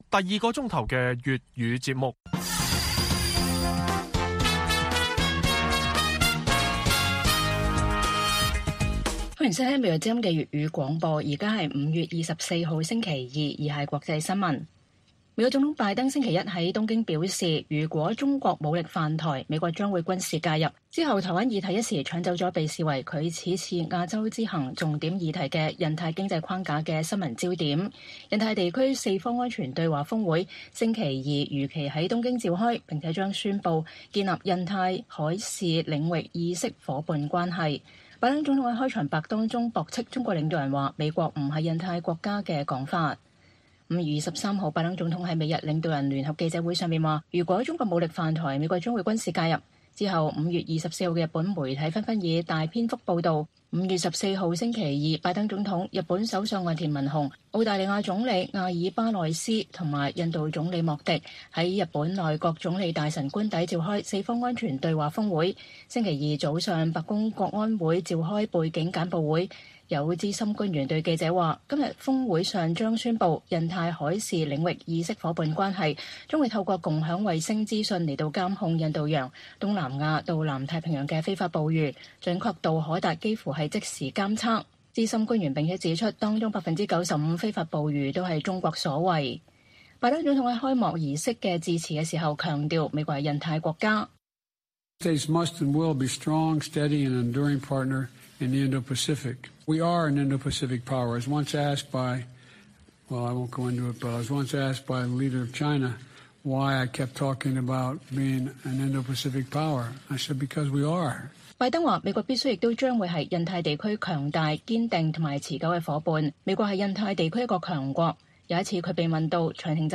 粵語新聞 晚上10-11點: 四方機制峰會在東京召開